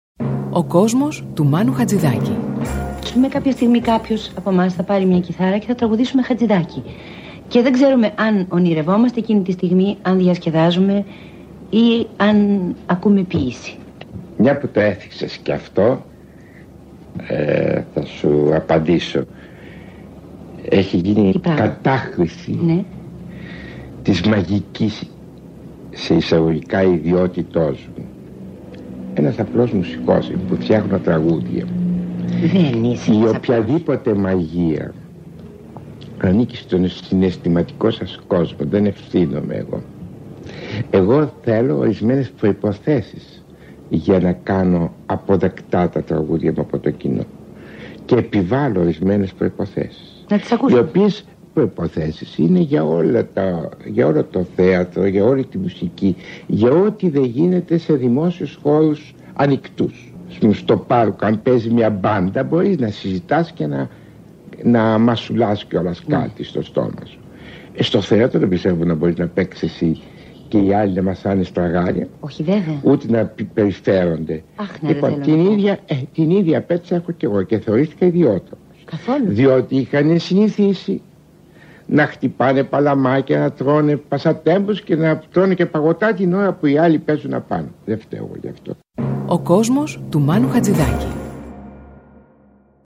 Ακούμε τη φωνή του Μάνου Χατζιδάκι και μπαίνουμε στον κόσμο του.